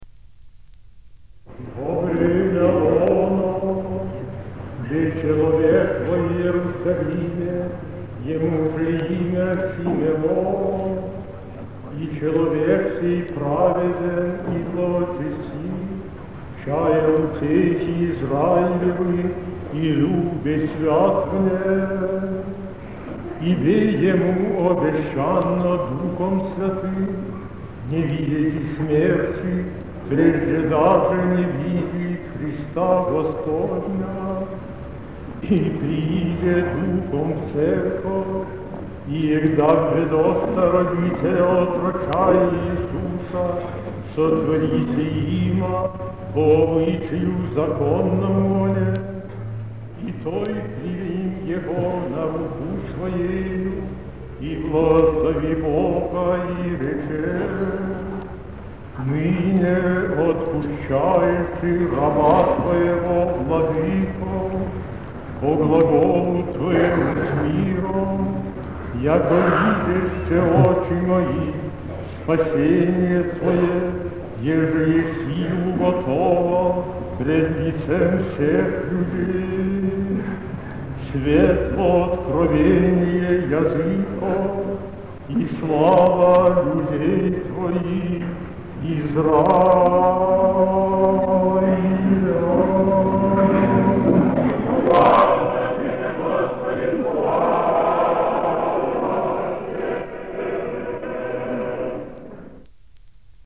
Запись с пластинки "Патриарх Алексий (Симанский) и его время"
5. Евангелие на Всенощной Праздника Сретения Господня